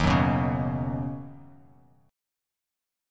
D#m7#5 chord